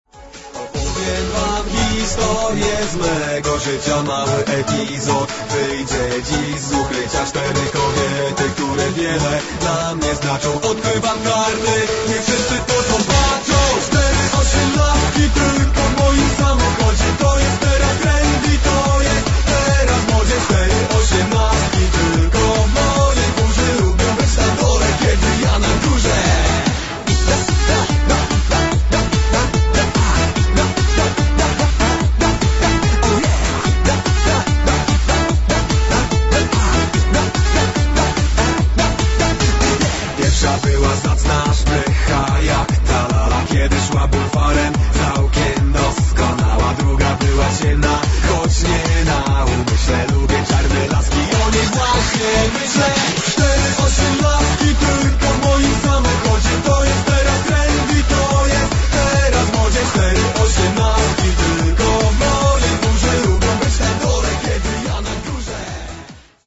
Instrumenty klawiszowe